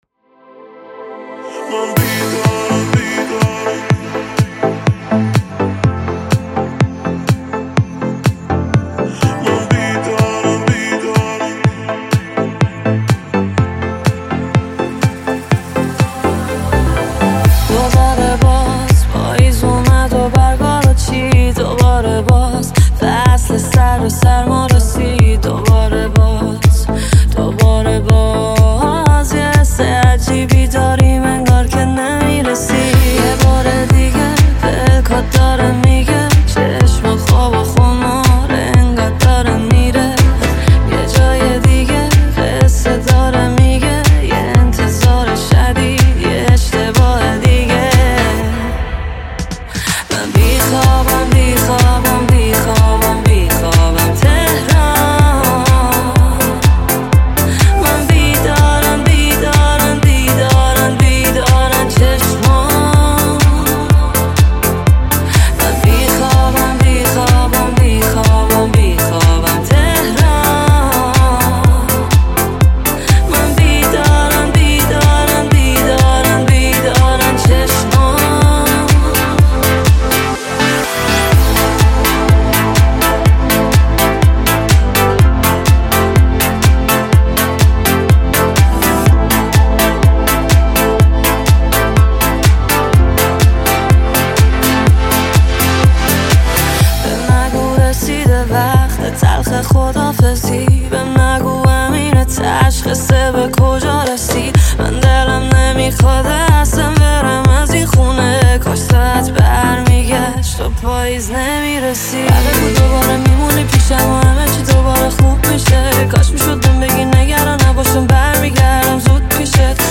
پاپ شاد رقص عاشقانه